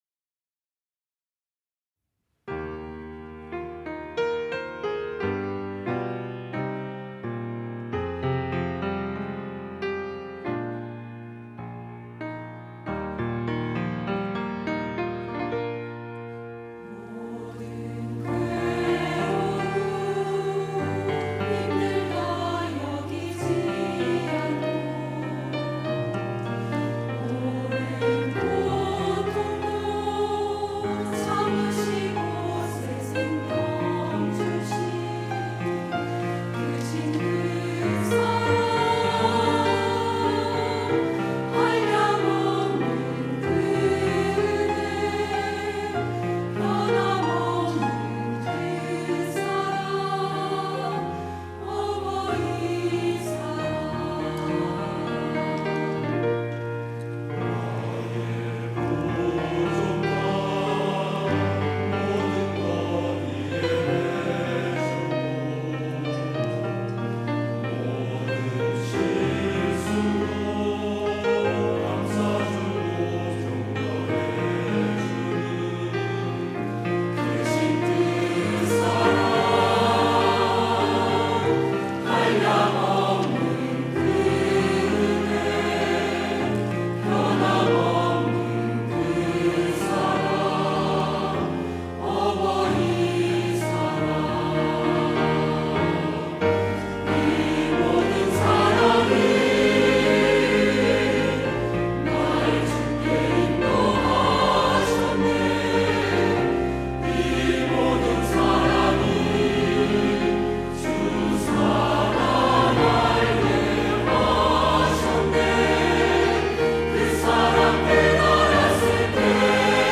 할렐루야(주일2부) - 어버이의 사랑
찬양대